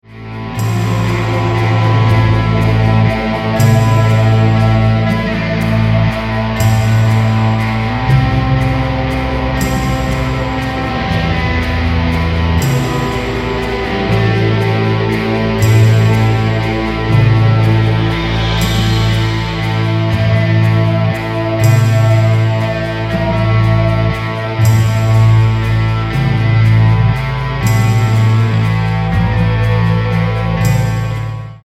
loops, drums & percussion, main guitar, noises
bass guitar, keys
lead guitar, keys, transmissions